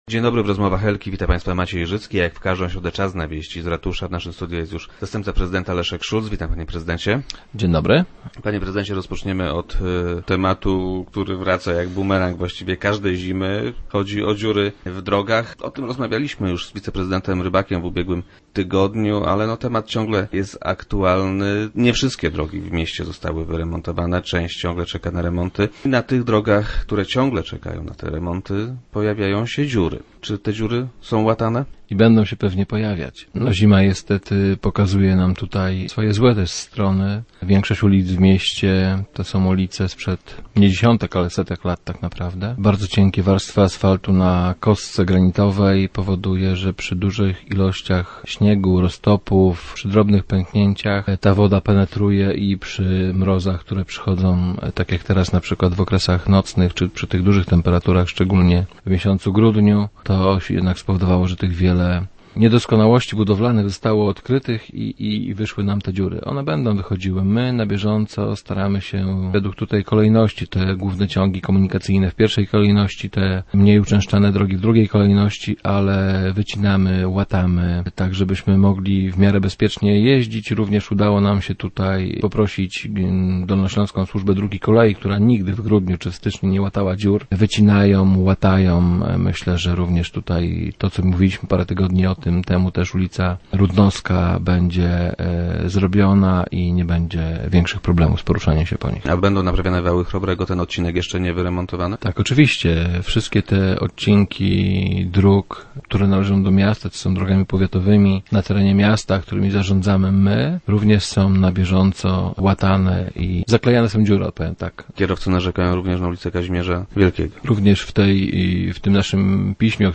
- W pierwszej kolejności robimy to na głównych ciągach komunikacyjnych, w drugiej kolejności na mniej uczęszczanych ulicach - powiedział nam wiceprezydent Szulc, który był gościem dzisiejszych Rozmów Elki.